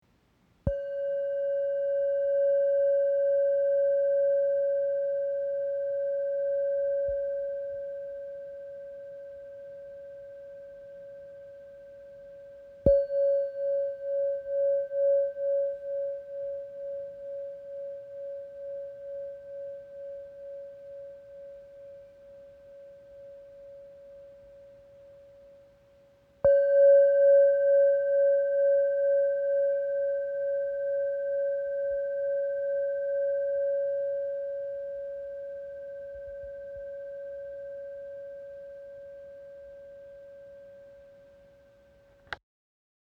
Bol chantant 7 métaux • Do# 558 Hz
Composé de sept métaux martelés à la main par des artisans expérimentés au Népal.
Note : Do# 558 Hz
Diamètre : 11,2 cm